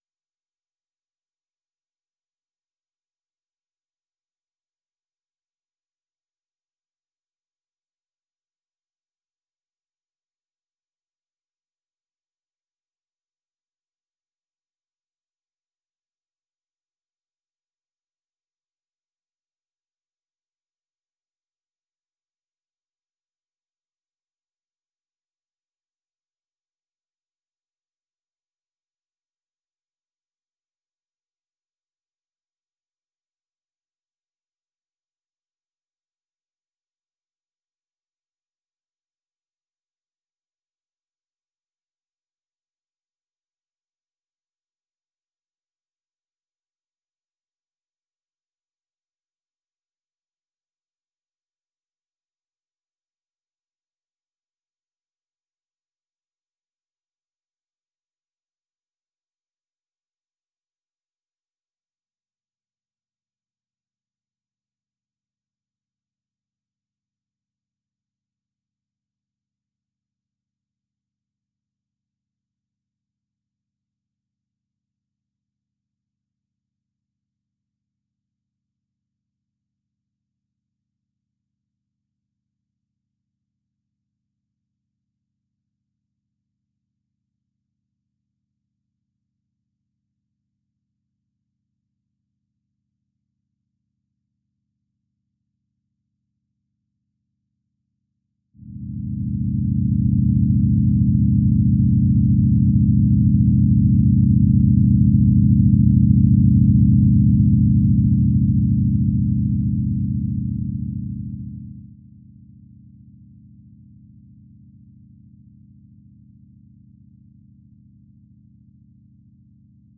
The resulting sound reached 90 -dB the maximum sustained volume allowed in the municipal zone.